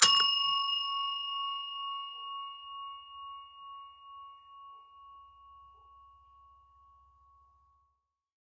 Reception Bell
bell chime counter desk ding hotel office reception sound effect free sound royalty free Sound Effects